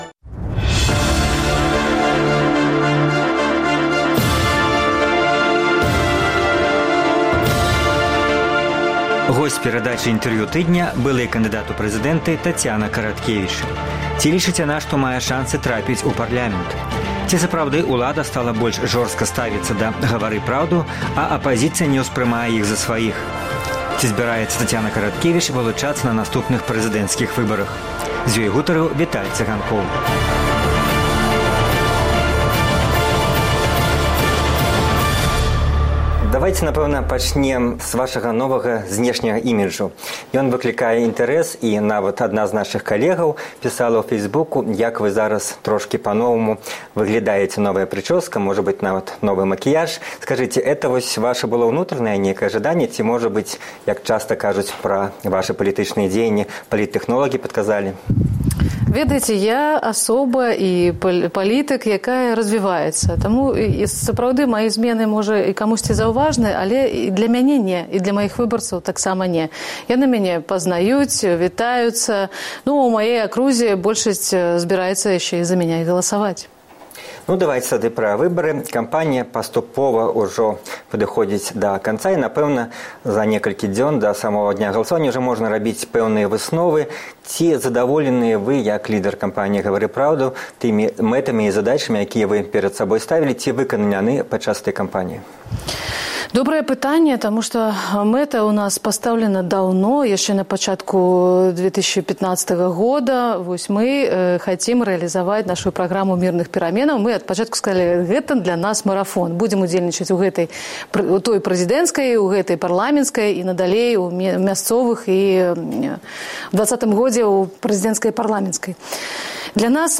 Госьць перадачы “Інтэрвію тыдня” -- былы кандыдат у прэзыдэнты Тацяна Караткевіч.